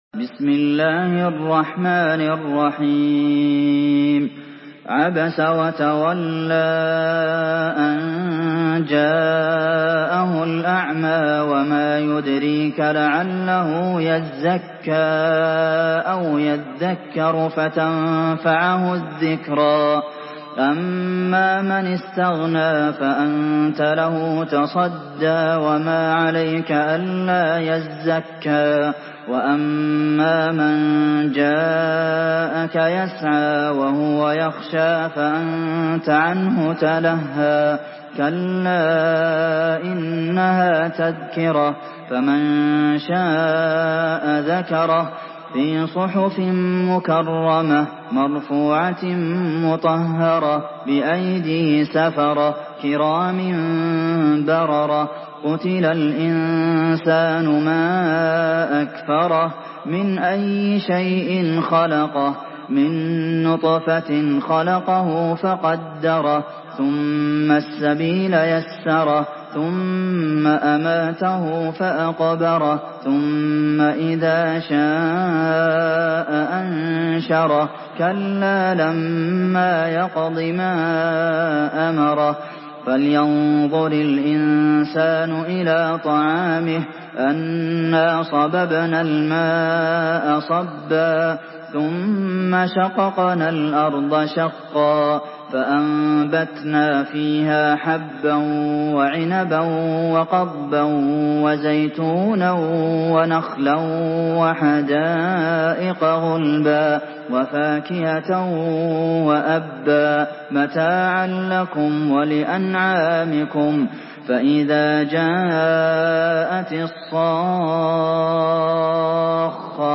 Surah Abasa MP3 by Abdulmohsen Al Qasim in Hafs An Asim narration.
Murattal Hafs An Asim